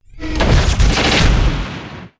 bellato_guardtower_create.wav